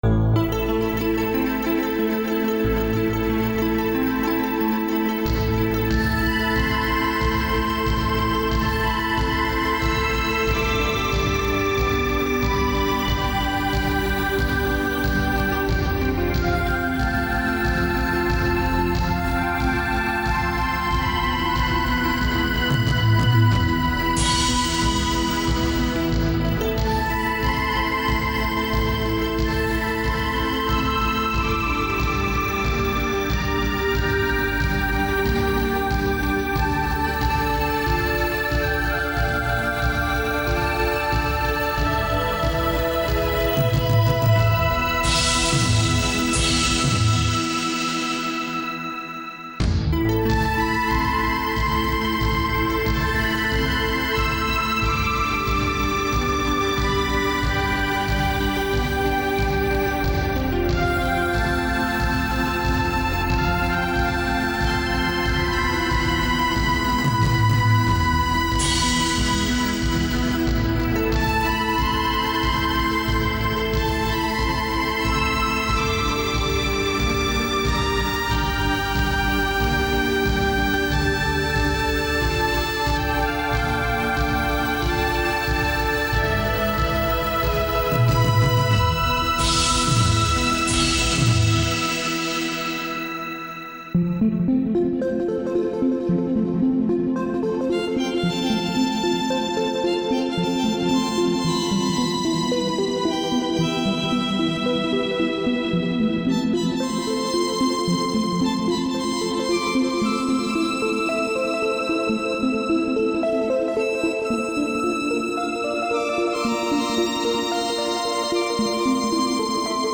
Driving instrumental with synth and percussion.
Tempo (BPM) 92